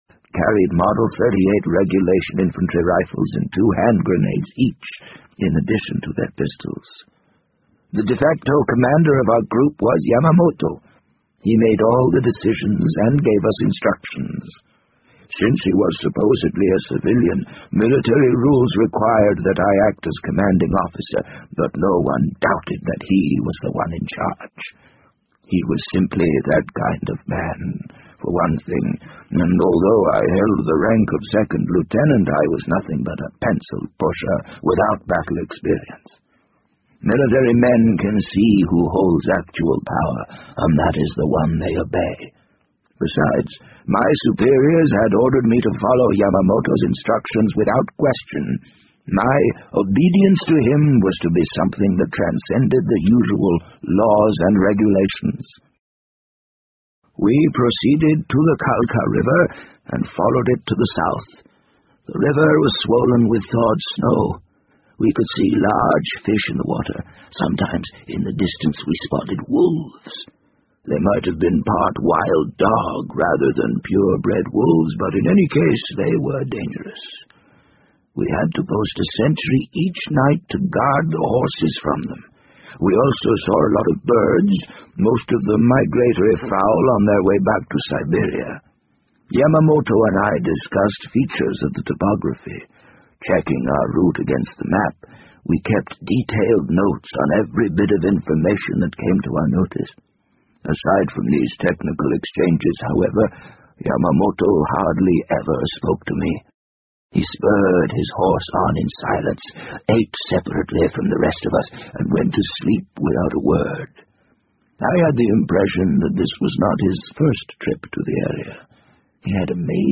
BBC英文广播剧在线听 The Wind Up Bird 004 - 6 听力文件下载—在线英语听力室